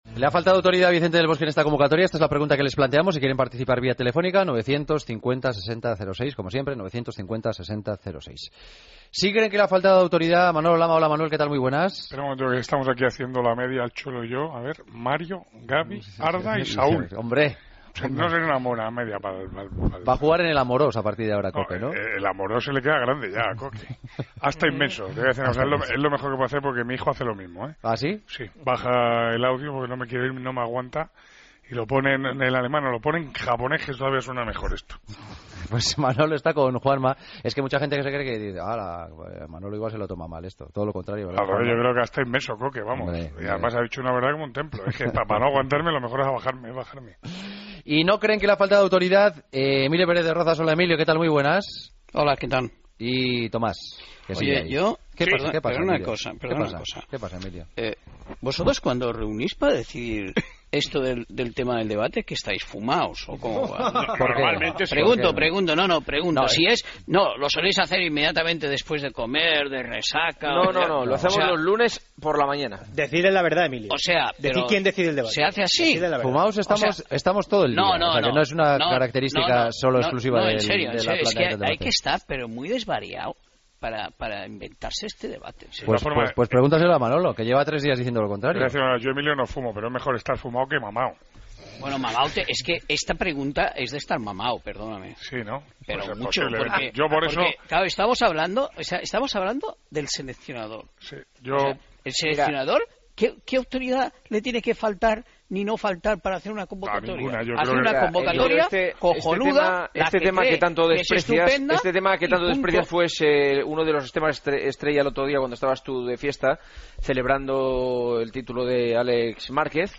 El debate de los jueves: ¿Le ha faltado autoridad a Del Bosque en esta convocatoria?